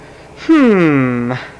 Hmmmmm Sound Effect
hmmmmm.mp3